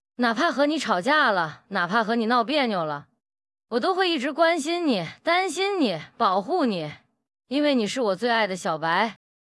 同时，哪吒也可以开口说蜡笔小新的经典台词，与其宠物小白隔空交流，复刻的音频中，既保留了哪吒的独特声线，还有其特殊的尾音上扬特征。
这些音频的实现效果，有的仅需要原角色不到5s的音频就能实现，且可以看出上面几段音频都没有出现明显的卡顿，还复刻了声调上扬、语速、说话节奏等细微的特征。